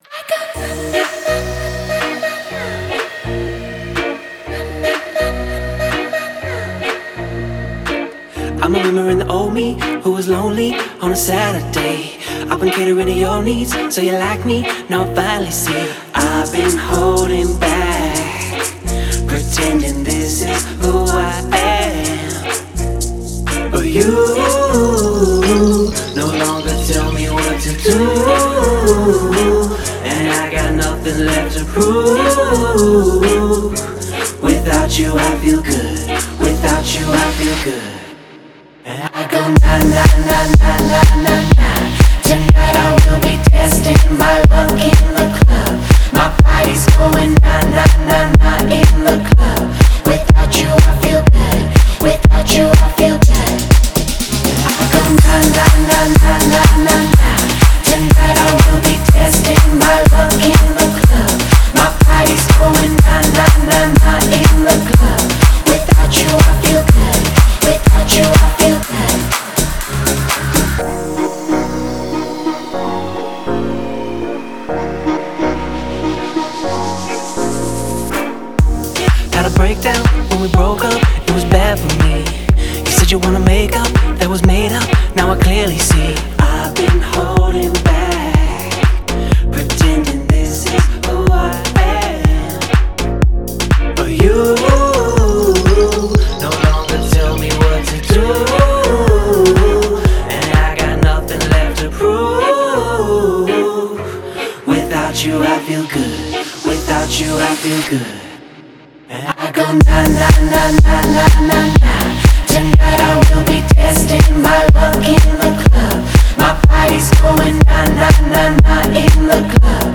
это энергичная поп-электронная композиция